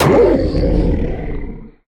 Minecraft Version Minecraft Version latest Latest Release | Latest Snapshot latest / assets / minecraft / sounds / mob / wolf / big / death.ogg Compare With Compare With Latest Release | Latest Snapshot
death.ogg